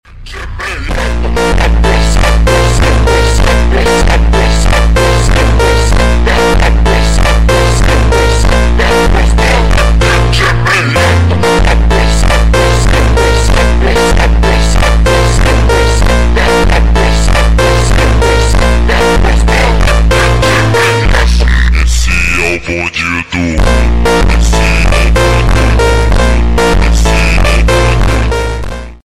(SUPER SLOWED + REVERB) [BRAZILIAN PHONK]